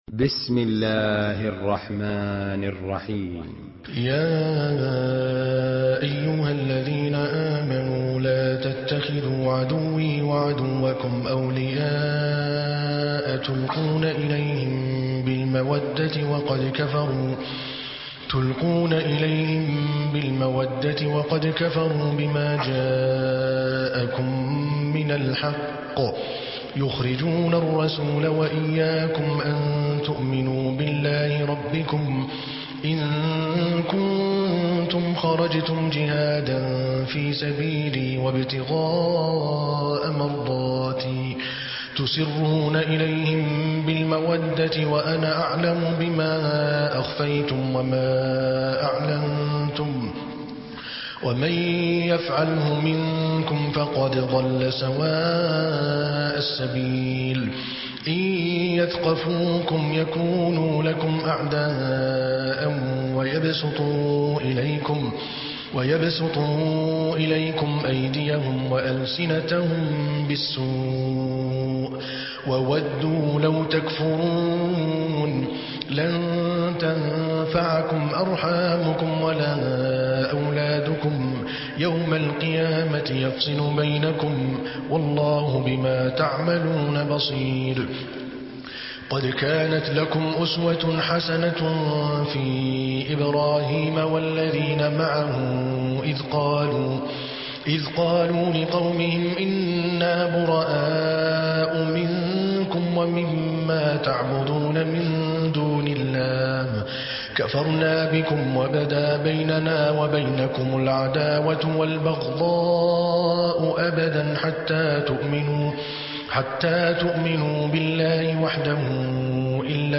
Surah Al-Mumtahinah MP3 by Adel Al Kalbani in Hafs An Asim narration.
Murattal